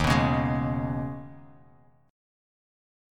Eb7sus4#5 Chord
Listen to Eb7sus4#5 strummed